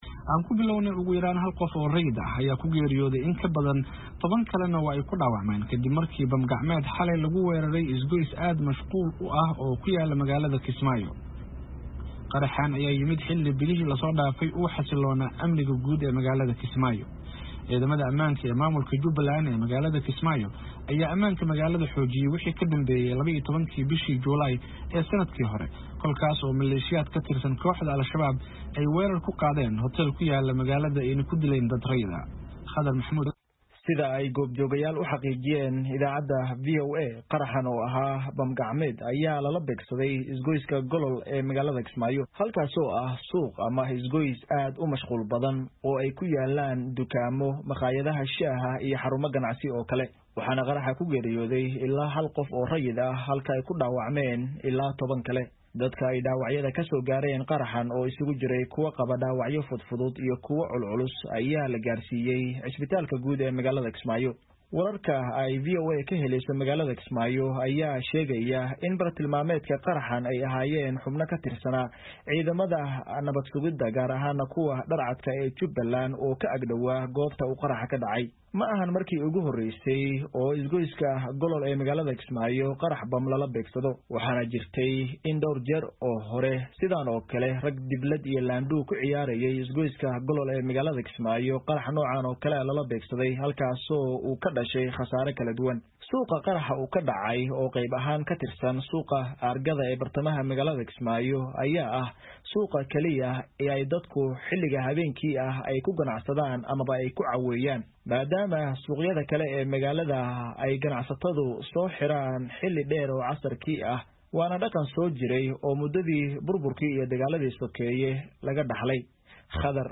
oo xiriiro kala duwan la sameeyey magaalada ayaa warbixintan ka soo diray magaalada Nairobi.